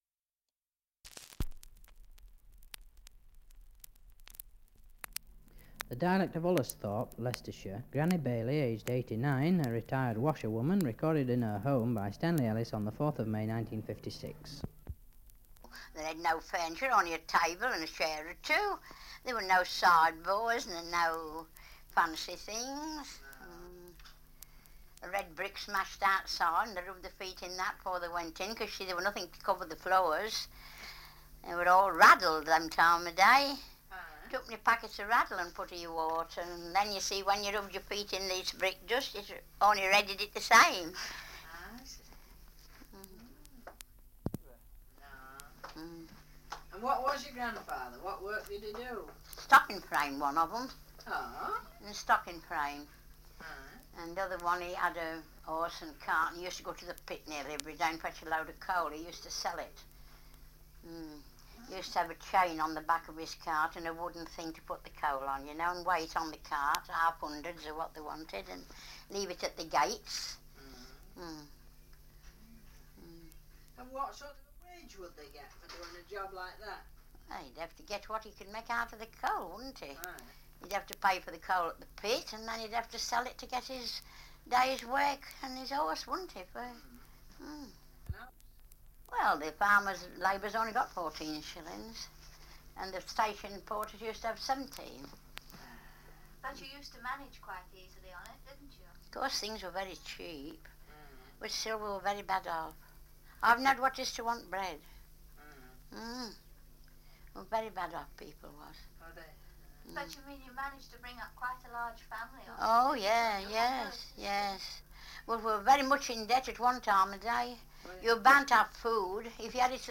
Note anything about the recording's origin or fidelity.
1 - Survey of English Dialects recording in Ullesthorpe, Leicestershire. 78 r.p.m., cellulose nitrate on aluminium